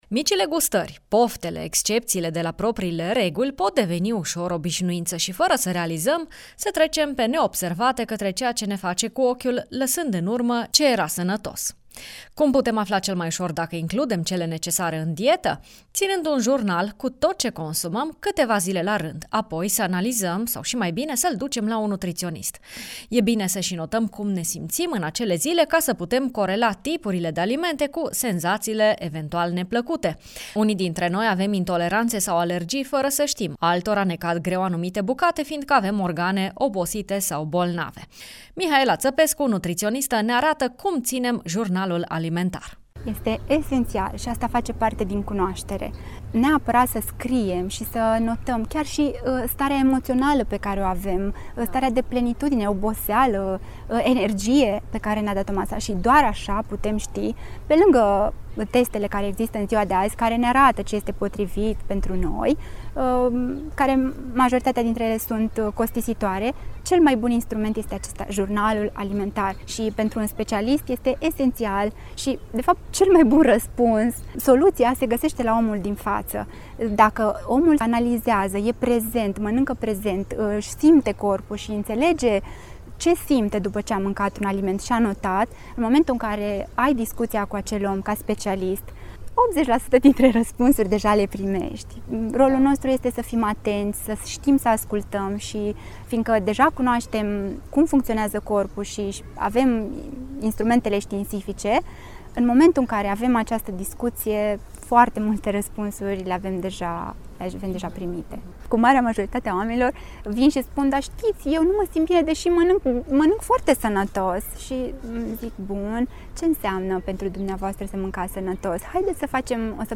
nutriționist: